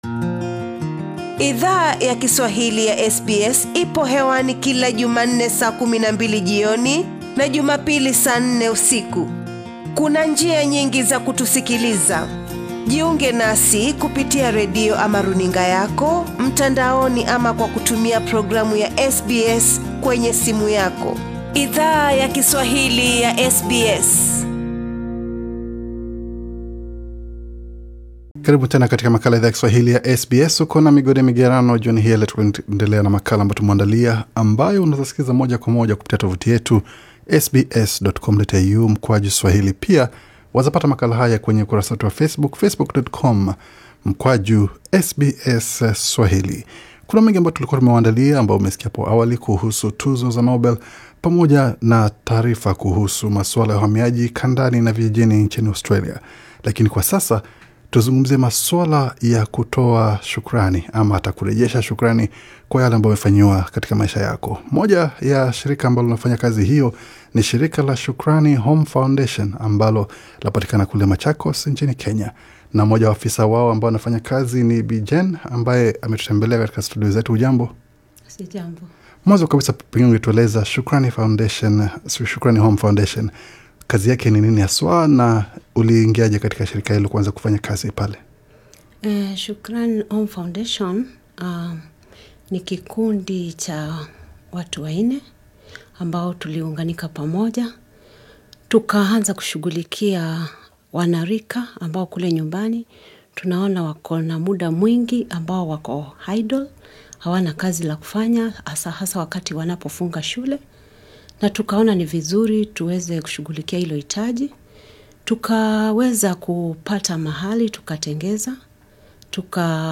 mahojiano